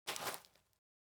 Illusion-UE5/Dirt Walk - 0003 - Audio - Dirt Walk 03.ogg at dafcf19ad4b296ecfc69cef996ed3dcee55cd68c